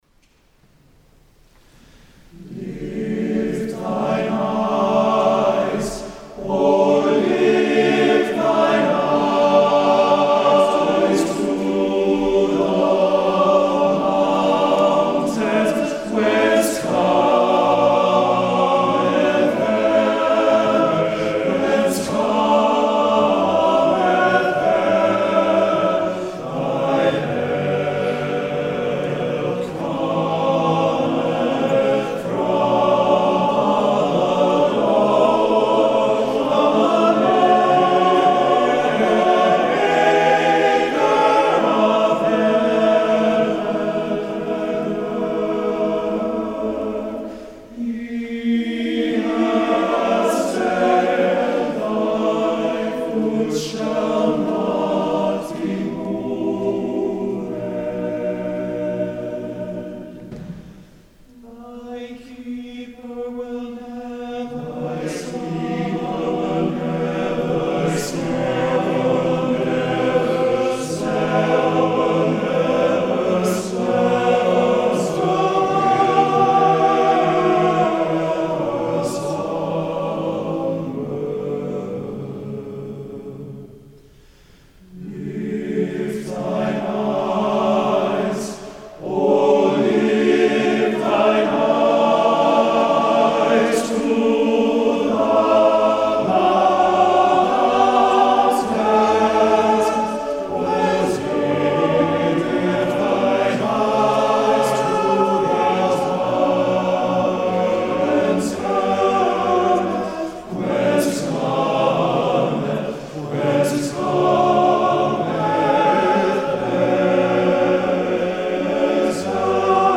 TTBB choir a cappella.